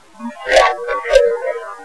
We had with us a camcorder, digital camera, and three recorders.
Because the voices recorded there were "faint", they had to be amplified and filtered using our computer program.
Because of this, you can expect to hear some white noise "harmonics" mixed in with the voices.
In any regard...the voices are still audible and a few of them are kind of startling in their clarity.
The next spirit voice we recorded was from this fairly clear speaking man.